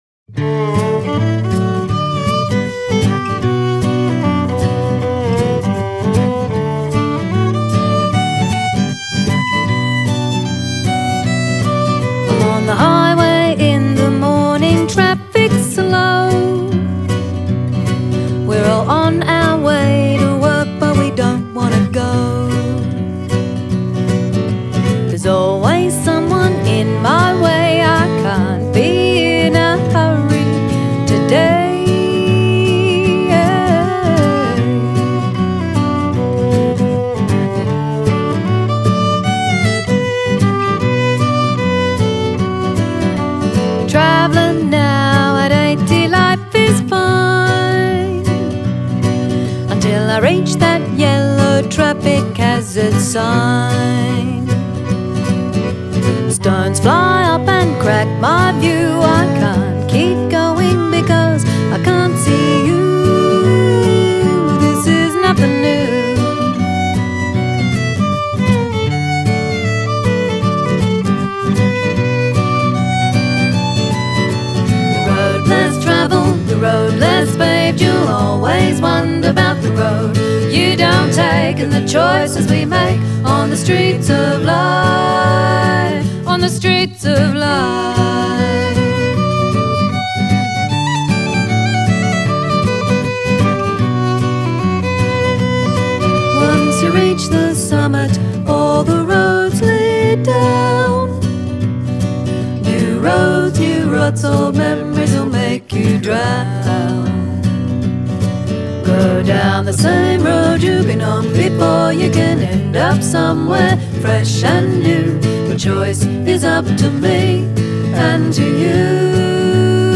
Folk Rock Traditional Roll